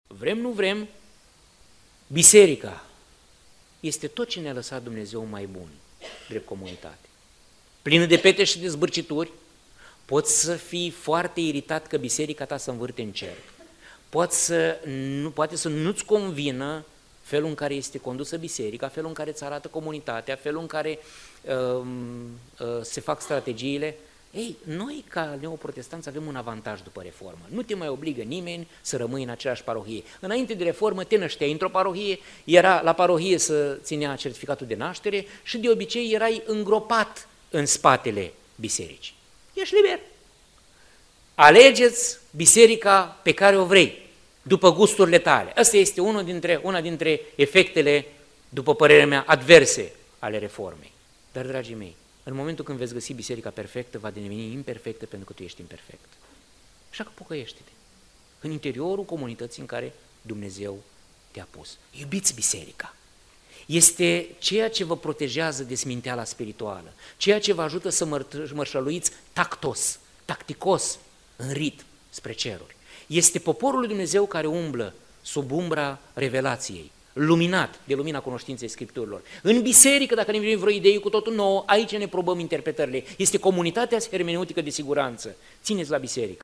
Această nouă serie se va desfășura sub titlul ”CREDO”. În acest prim mesaj a evidențiat și un efect advers al Reformei Protestante.